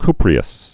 (kprē-əs, ky-)